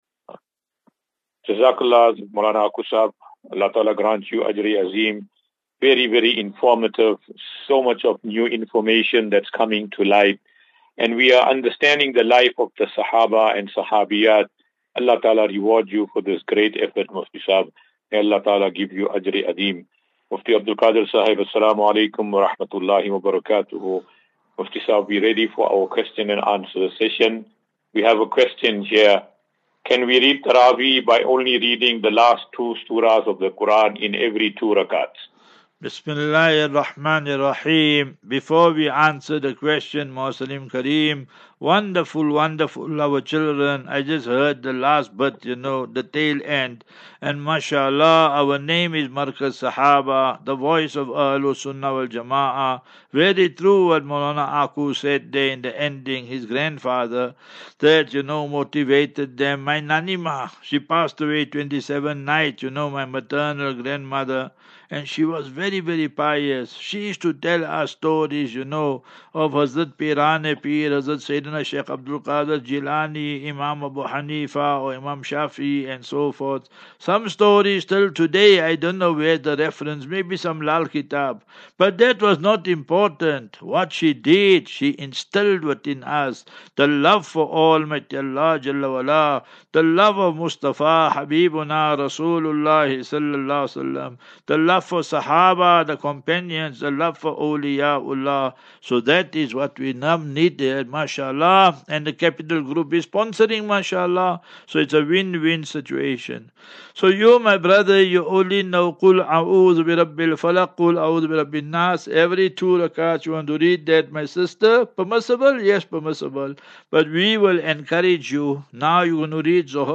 As Safinatu Ilal Jannah Naseeha and Q and A 18 Mar 18 March 2024.